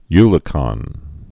(ylə-kŏn)